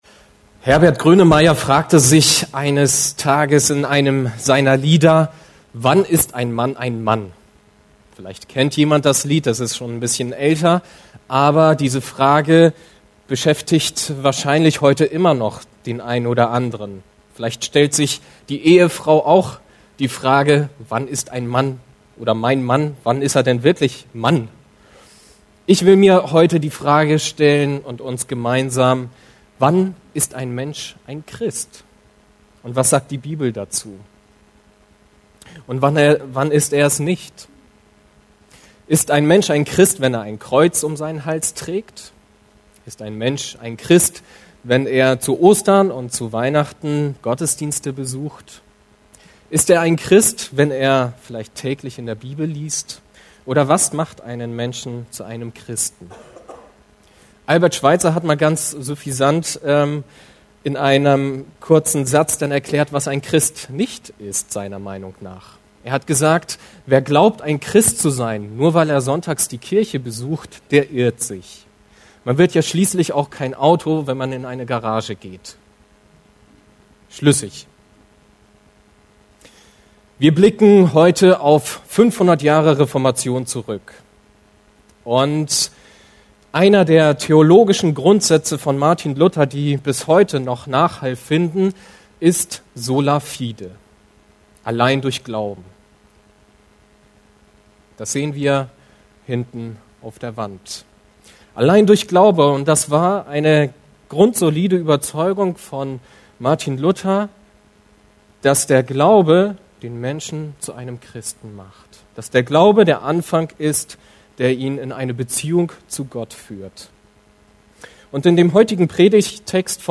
Einzelpredigten